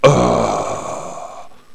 daemon_die3.wav